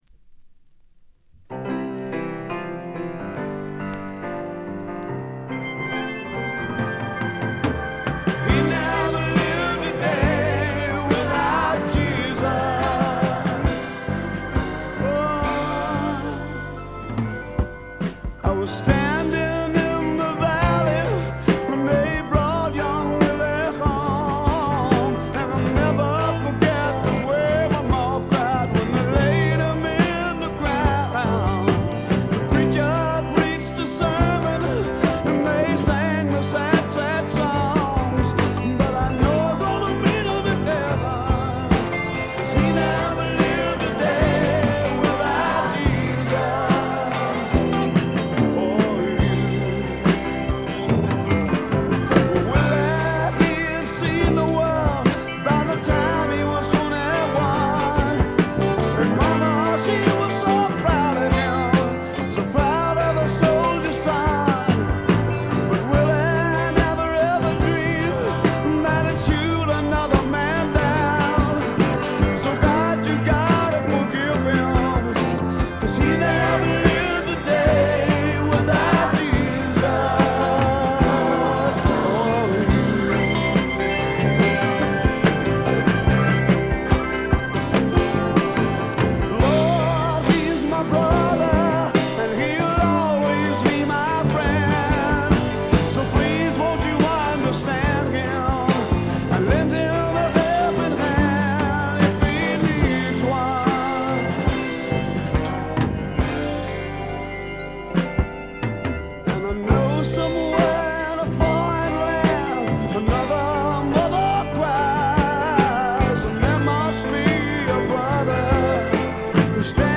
部分試聴ですがチリプチ少々程度。
スワンプ・ロックの基本定番！！！
試聴曲は現品からの取り込み音源です。
January 1971 Olympic Sound Studios, Barnes, London